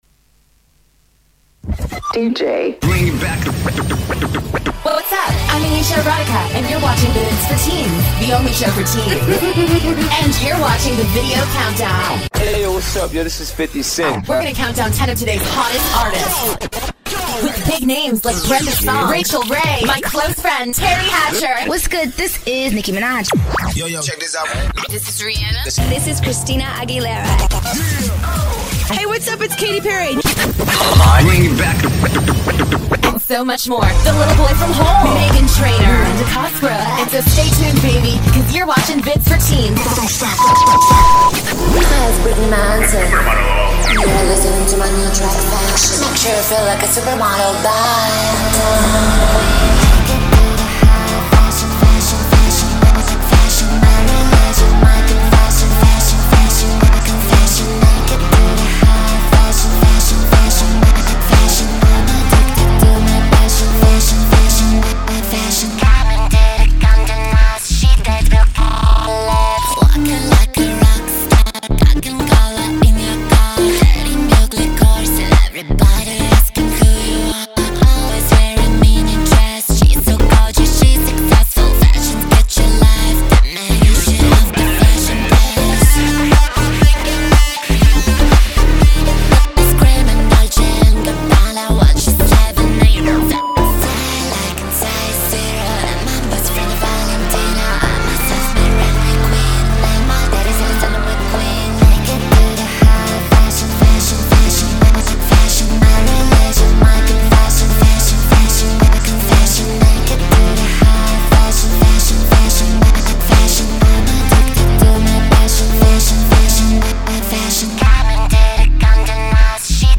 Sound: Stereo